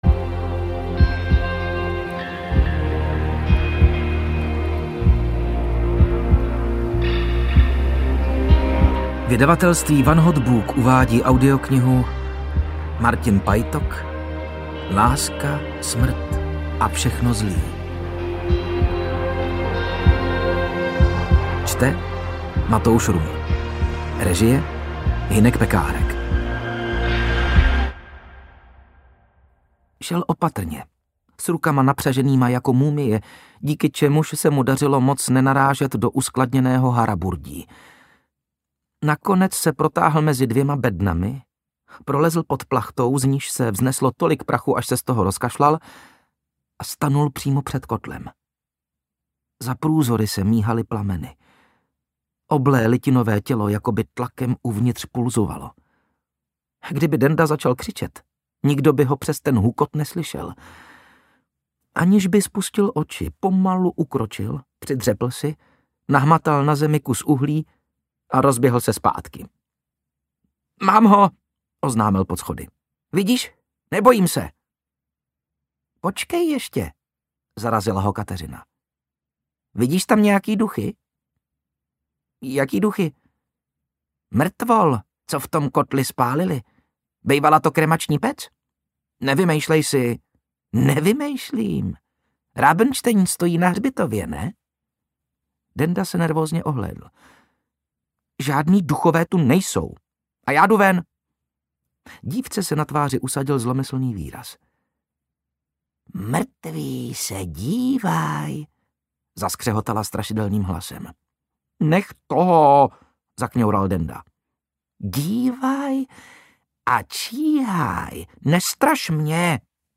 Láska, smrt a všechno zlý audiokniha
Ukázka z knihy